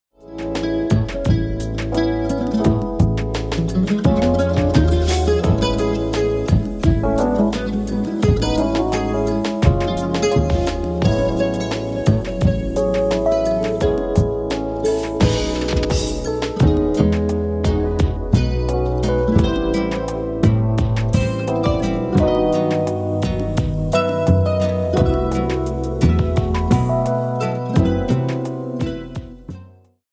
electric piano